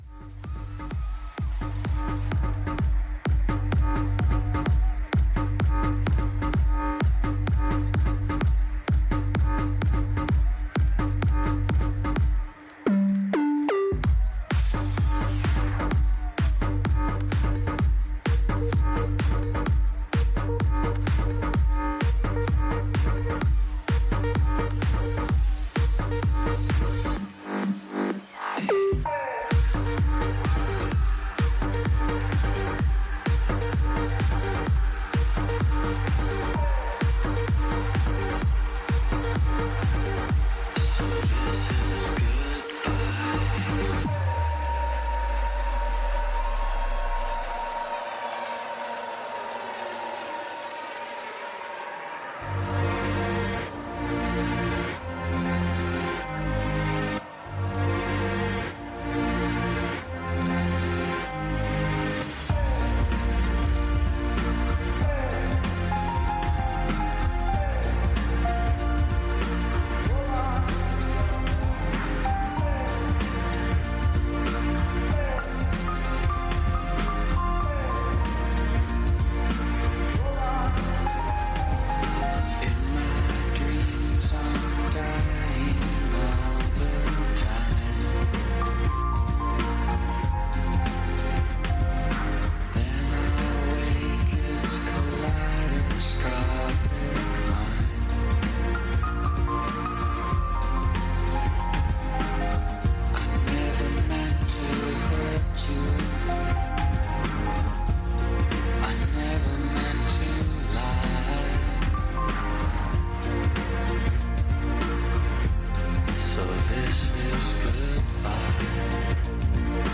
Also find other EDM Livesets,
Liveset/DJ mix